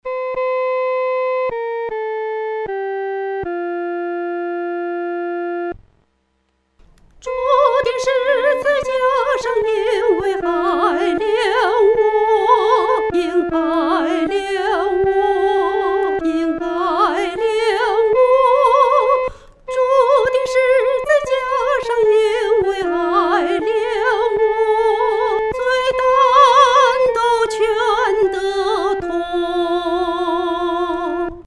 主钉十架因爱憐我-独唱（第一声）.mp3